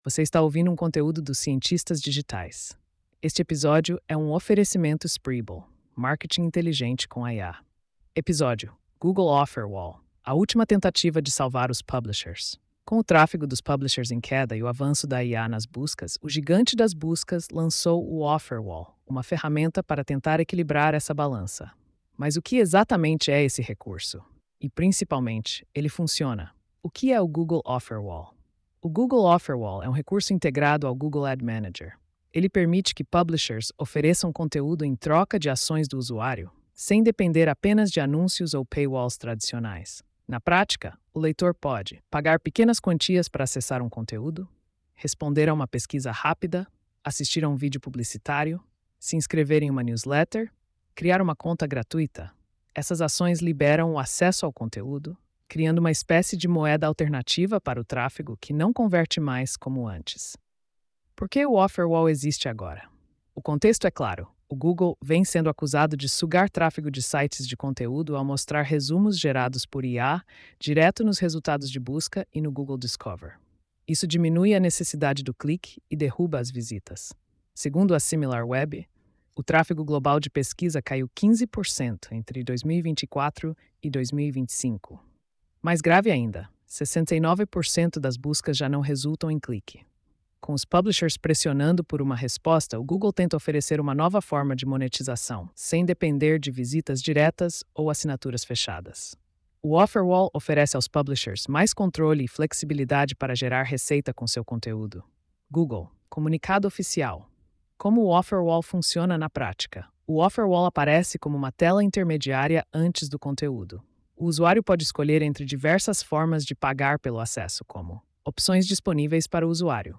post-3362-tts.mp3